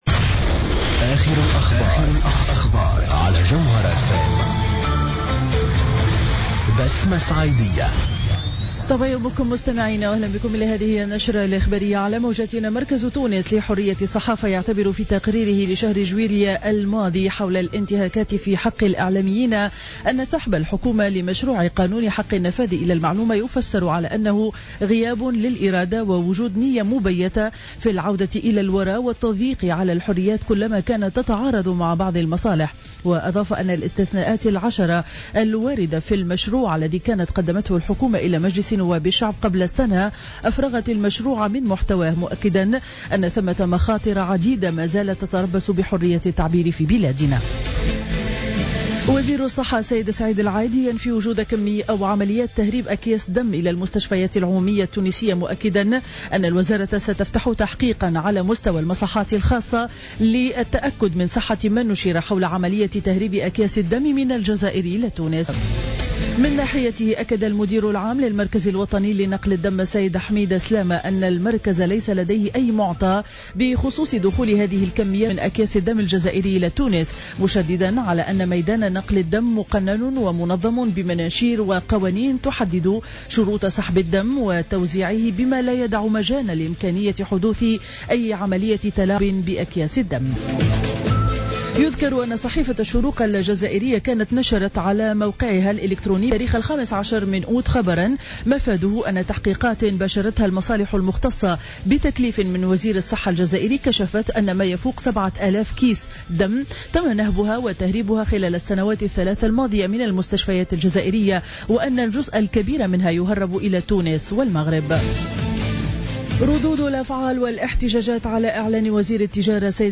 Journal Info 07h00 du mercredi 19 août 2015